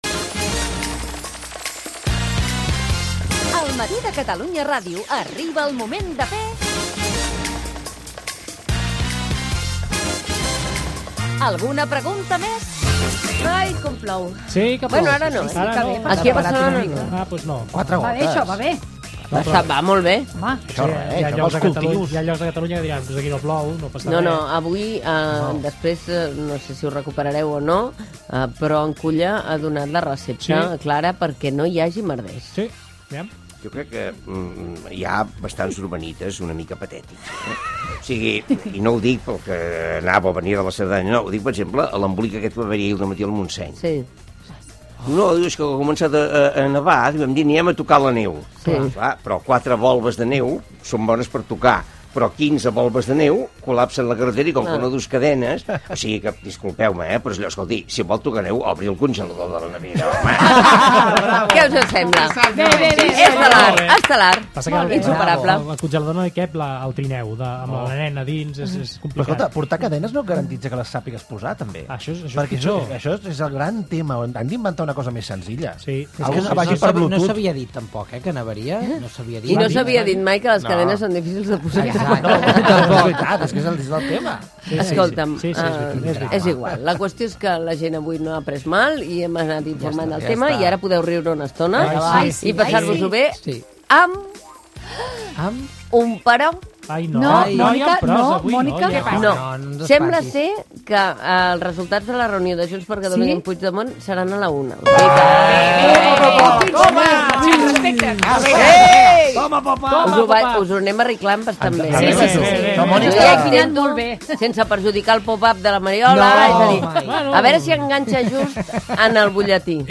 Espai 322 d'"Alguna pregunta més?" (APM): careta de l'espai, la neu, reunió de Junts per Catalunya, el jugador del F.C: Bracelona Gerard Piqué, la Super Bowl Gènere radiofònic Info-entreteniment